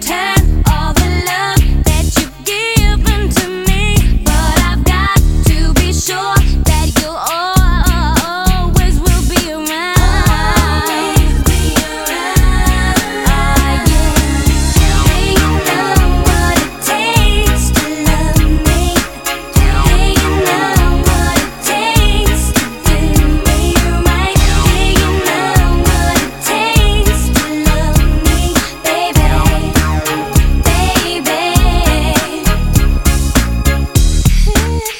Жанр: Поп музыка / R&B / Танцевальные / Электроника / Соул